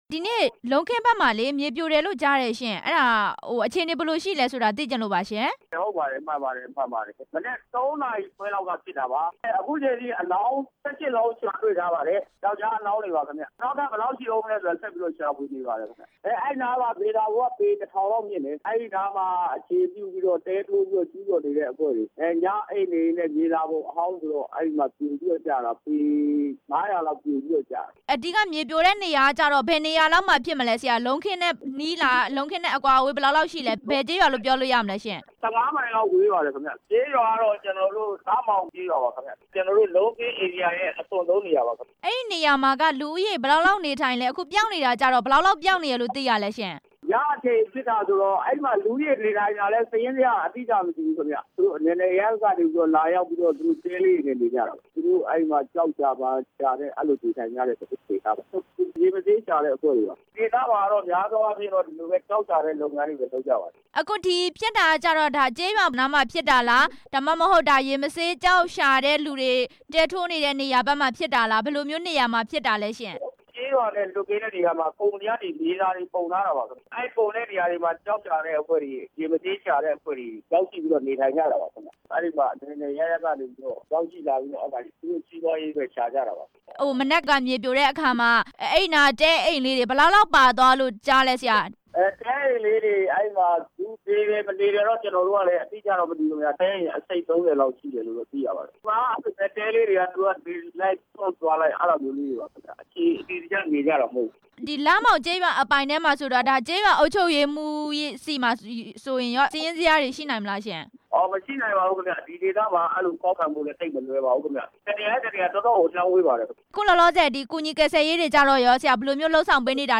ဖားကန့်မြို့နယ် မြေစာပုံပြိုကျတဲ့အကြောင်း မေးမြန်းချက်